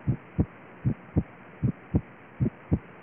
Bulhas normais
B1M hiperfonética, com sopro diastólico, com ruflar crescente, sopro mesodiastólico em ruflar com o reforço pre-sistólico, sopro paraesternal, esquerdo.